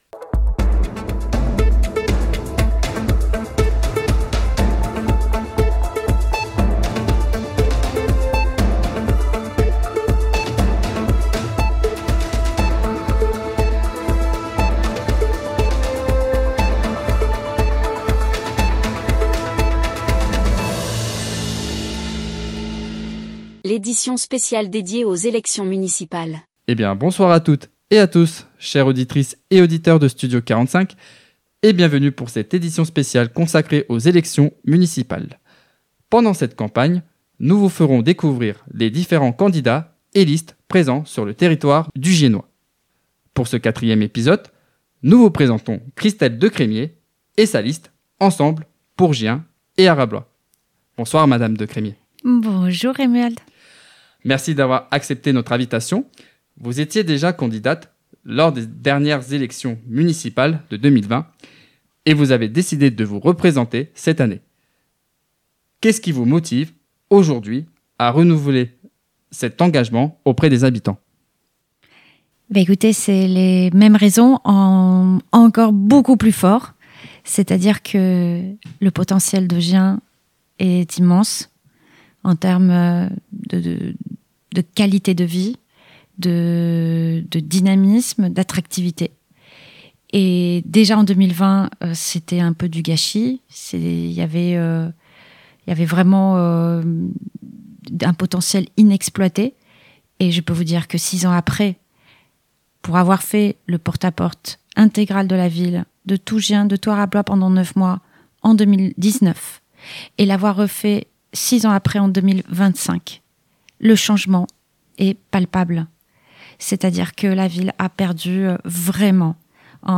???? Édition spéciale – Élections municipales : interview